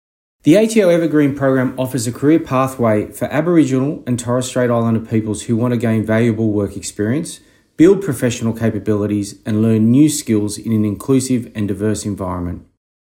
The following recordings feature ATO Deputy Commissioner and Indigenous Champion, Matthew Hay, discussing the 2022 Evergreen program.